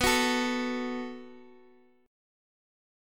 B57 chord {19 21 19 x x x} chord